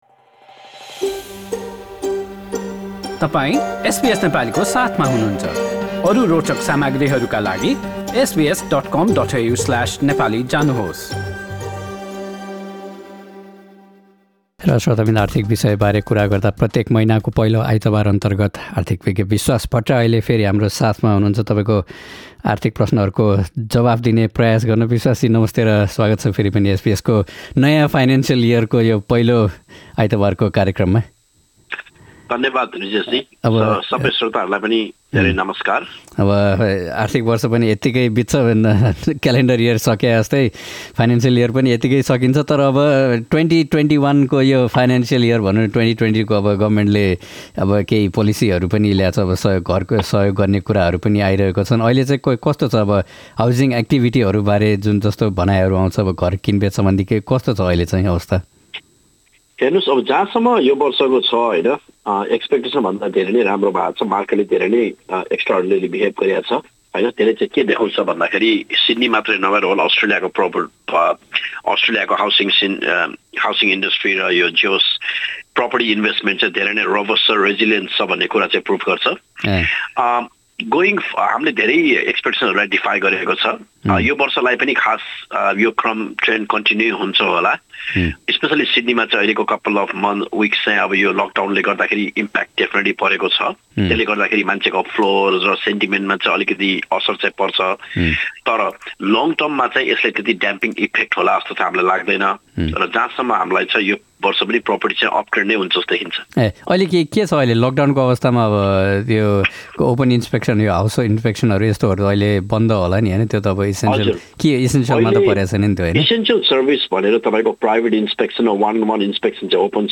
हाम्रो कुराकानी सुन्नुहोस्: